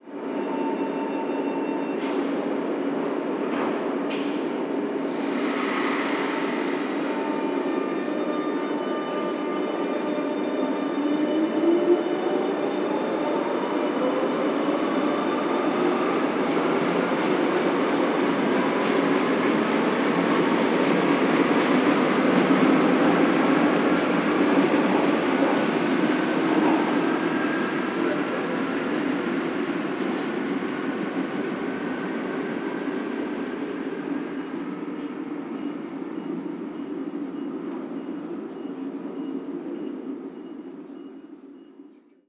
Tren saliendo de una estación
tren
Sonidos: Transportes